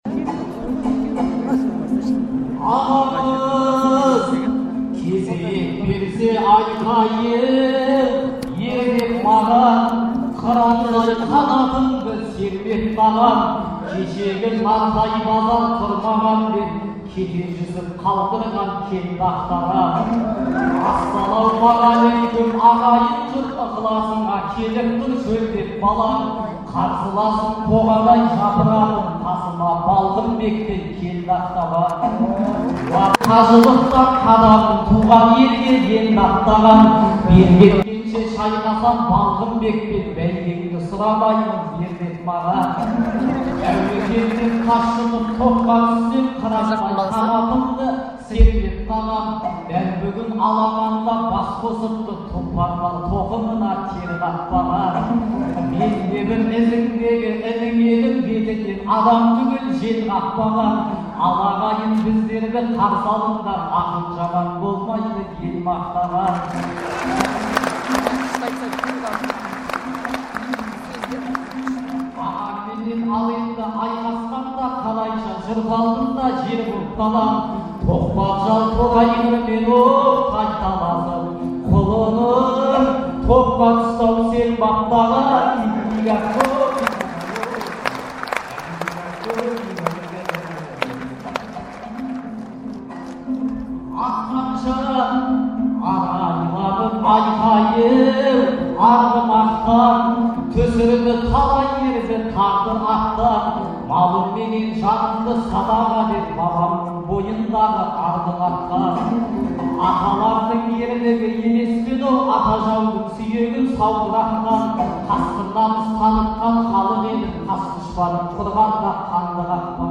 айтысы